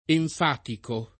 enfatico [ enf # tiko ] agg.; pl. m. ‑ci